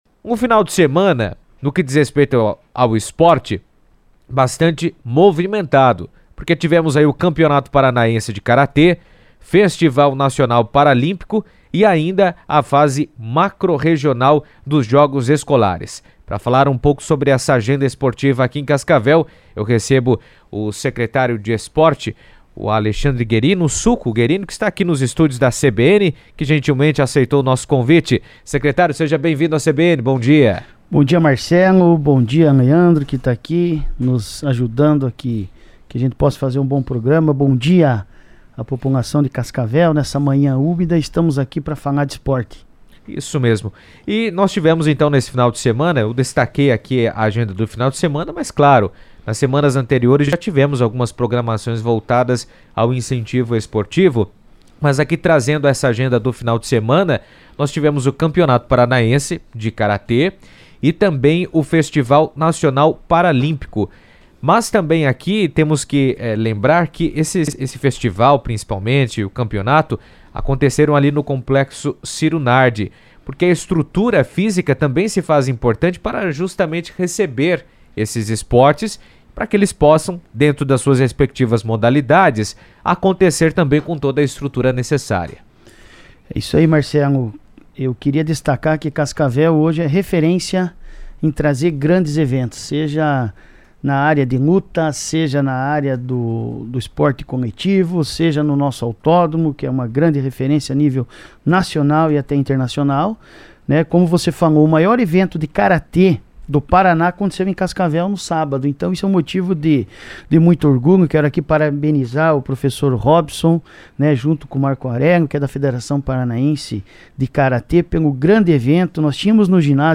O secretário de esportes de Cascavel, Alexandre Guerino (Suco), esteve na CBN e mencionou os inventimentos na área esportiva.